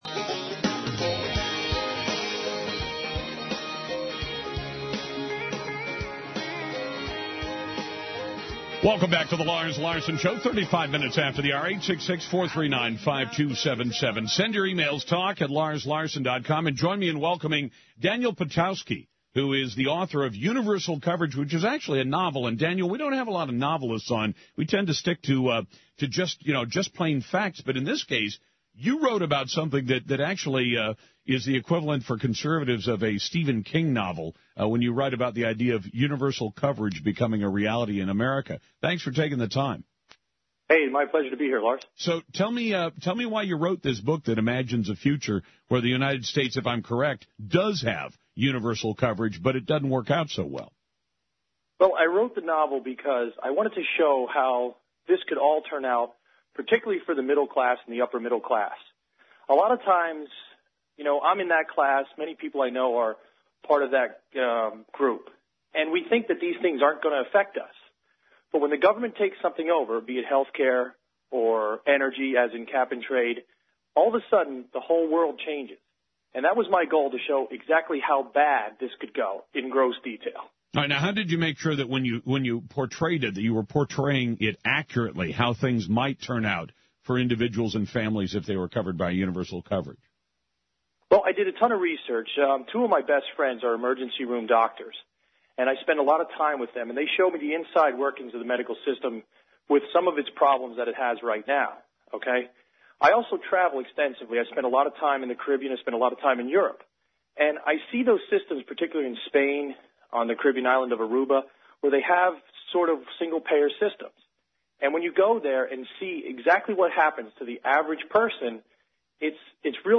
Nationally Syndicated Lars Larson Show/INTERVIEW